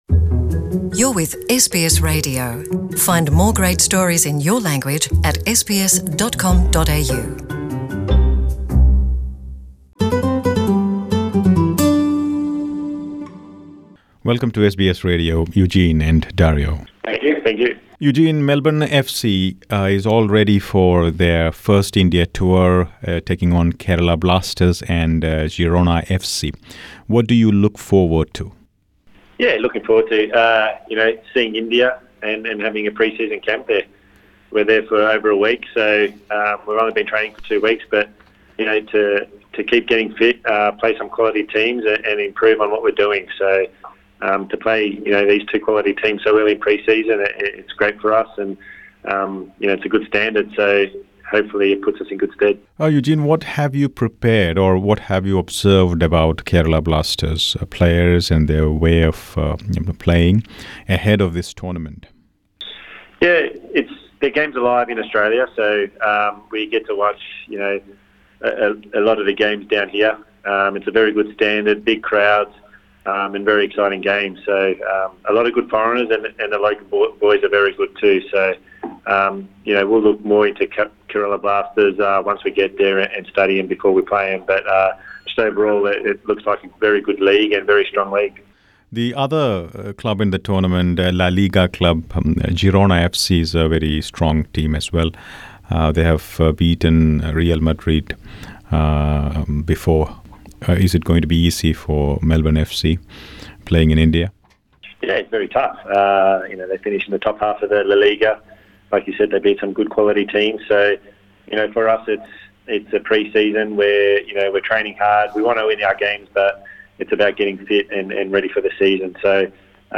Melbourne city striker Dario Vidosic and goal keeper Eugene Galekovic speak to SBS Malayalam about the preparations ahead of the Toyota Yaris La Liga World tournament at Jawaharlal Nehru International Stadium in Kochi. Melbourne City FC will take on ISL club Kerala Blasters and La Liga club Girona FC in the tournament starting July 24.